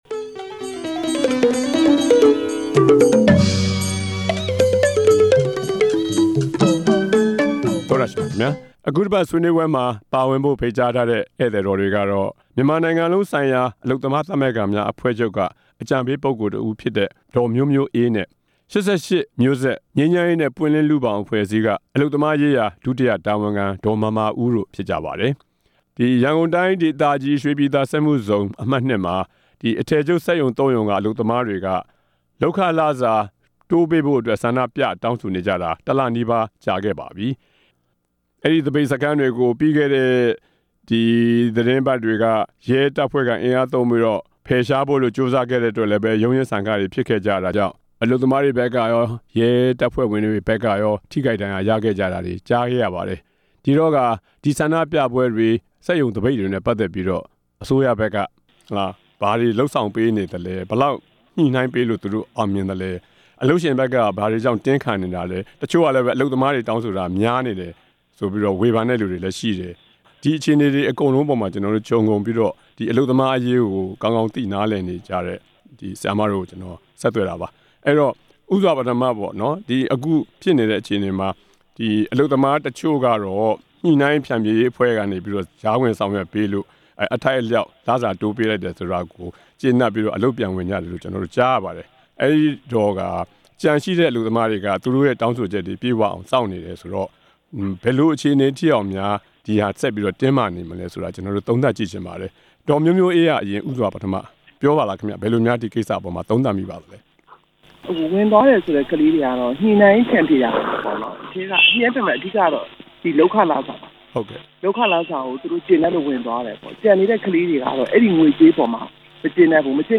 ရွှေပြည်သာ ဆန္ဒပြ အလုပ်သမားတွေ အကြောင်း ဆွေးနွေးချက်